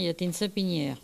Elle provient de Châteauneuf.
Locution ( parler, expression, langue,... )